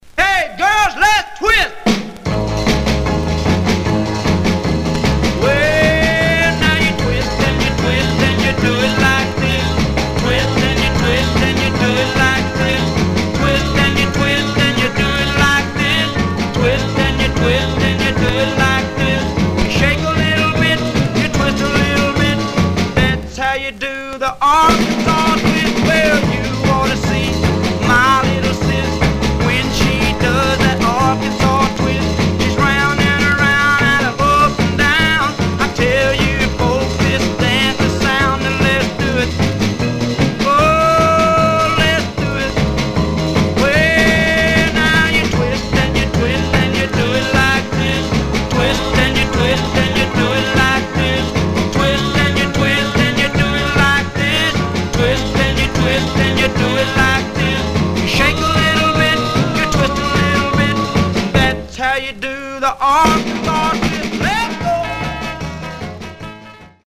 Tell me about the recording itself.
Some surface noise/wear Mono